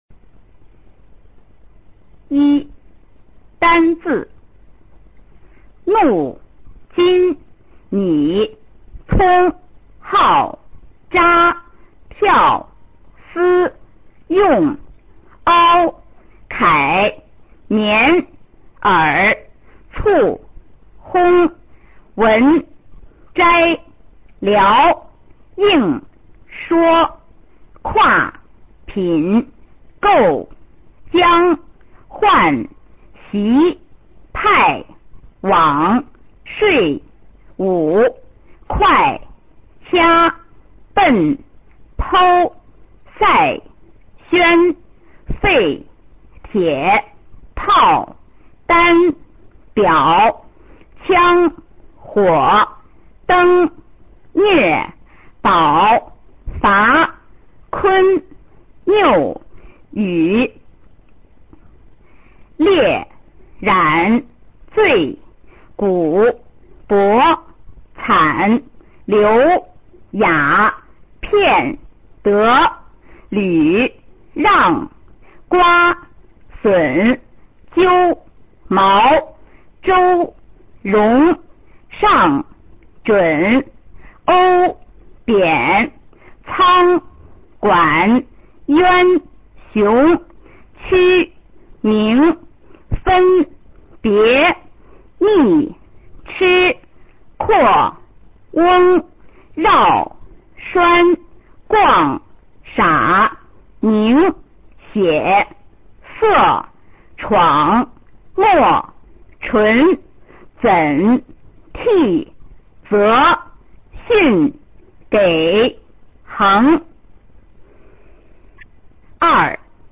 普通话水平测试一级甲等示范读音